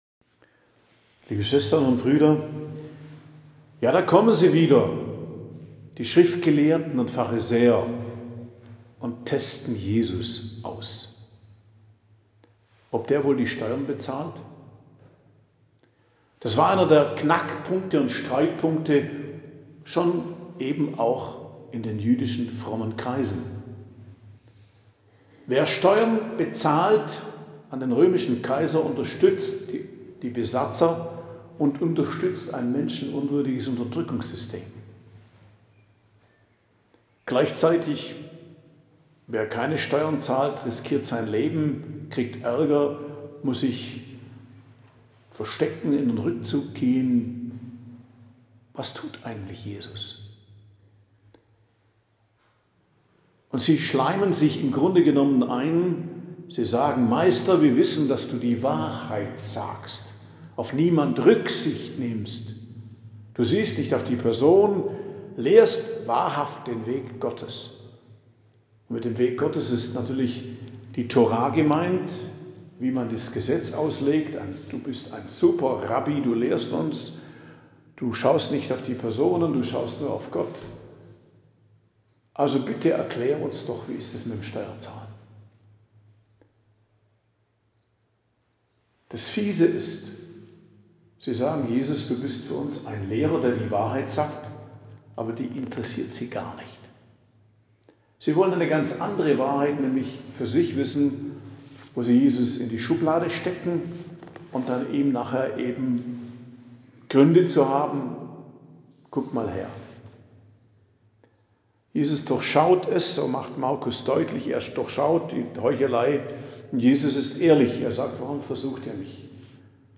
Predigt am Dienstag der 9. Woche i.J. 4.06.2024